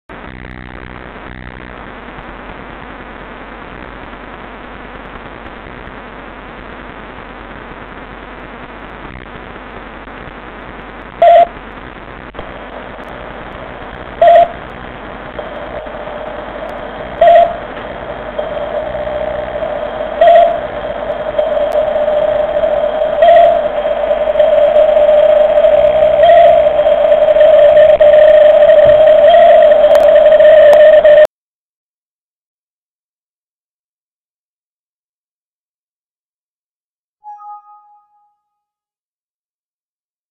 Si j'enclenche en plus le haut-parleur, il y a des bruits périodiques, et un effet du genre larsen, qui amplifie le son jusqu'à saturation.
Enregistrement audio au format WMA, ou le même au format MP3 : jusqu'à 11s, il n'y a que le micro. Après 11s, il y a en plus le haut parleur. Au cours de cet essai, Internet Explorer a planté à 31s. A 37s, on entend un son d'alerte Windows.